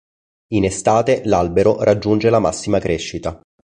e‧stà‧te
/esˈta.te/